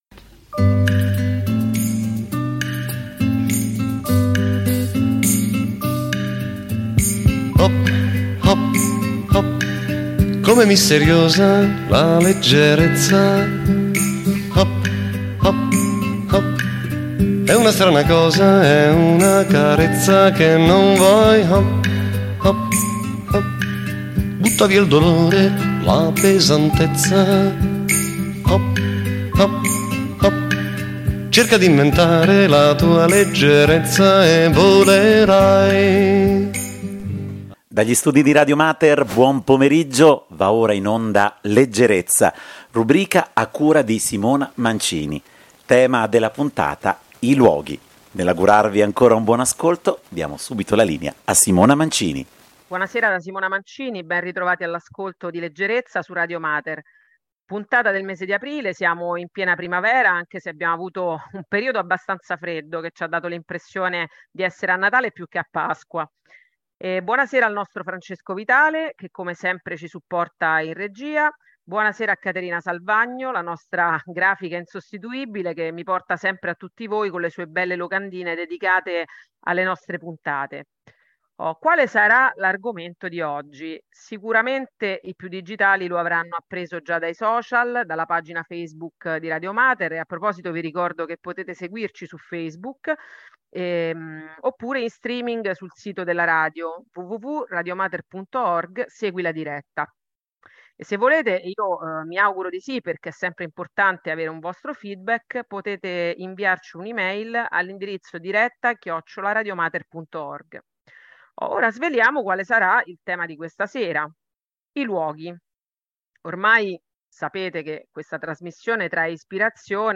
Sulle note di musiche emozionanti